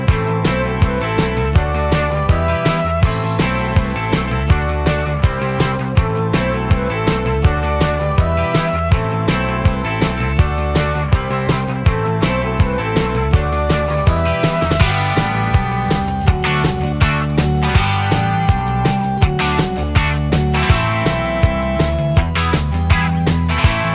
No Guitar Indie / Alternative 3:30 Buy £1.50